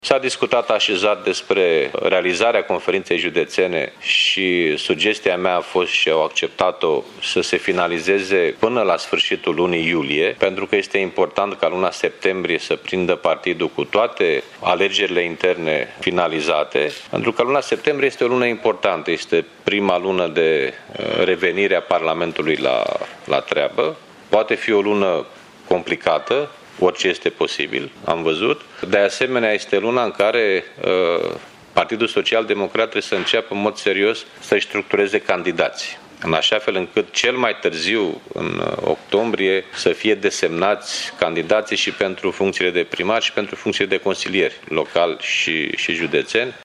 Prezent la ședința Comitetului executiv, Liviu Dragnea a explicat că în toată țara se vor organiza până la sfârșitul acestei luni Conferințe Județene, pentru a le da posibilitatea membrilor să se pregătească de alegerile locale.